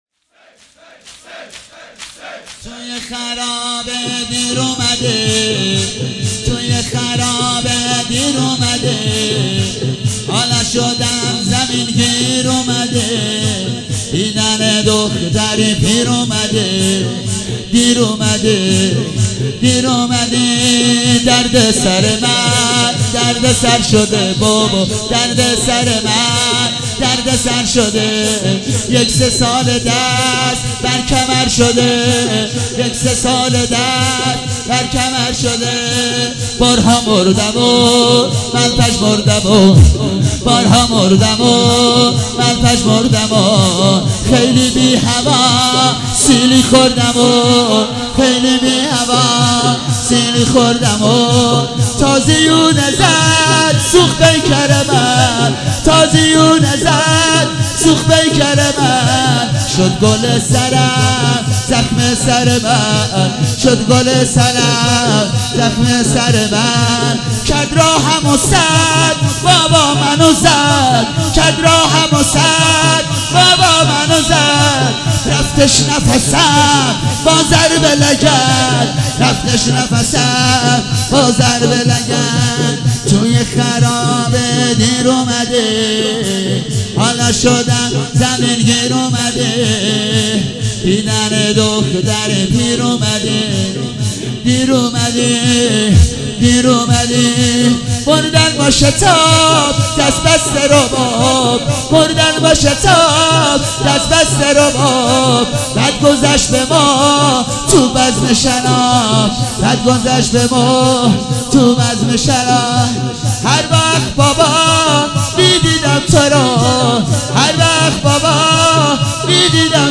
شب چهارم محرم96 - زمینه - توی خرابه دیر اومدی